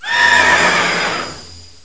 pokeemerald / sound / direct_sound_samples / cries / spectrier.aif